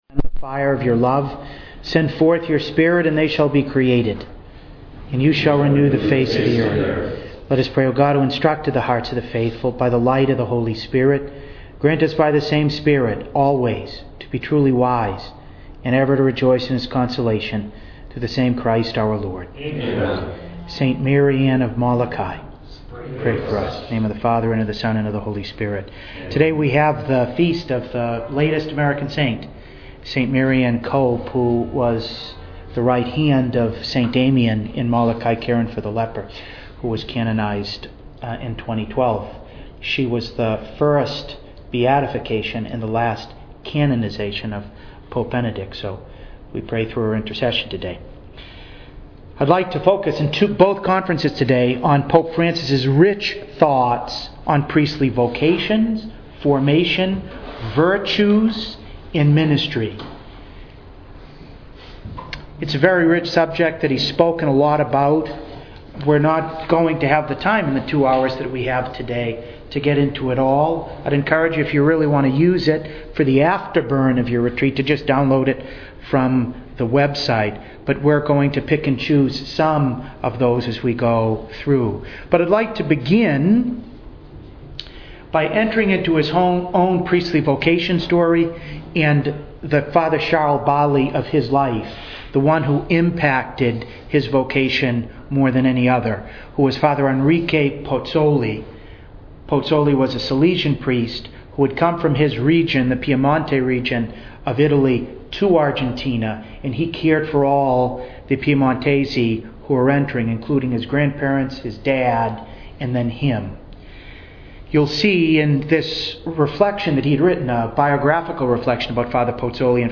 To listen to the first part of this two-part conference please click below: